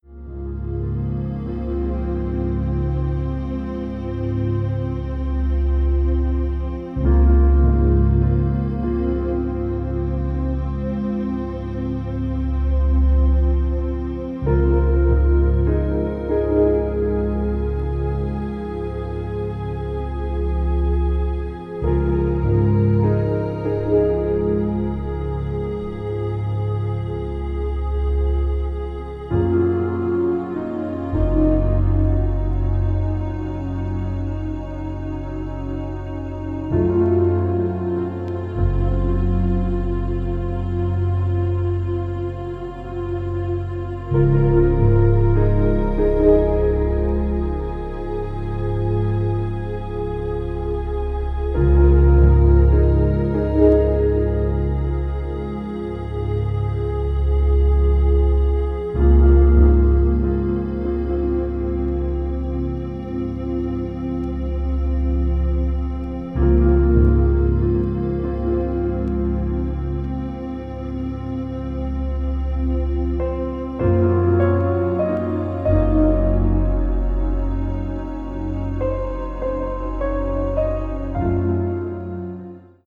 ambient   electronic   experimental   synthesizer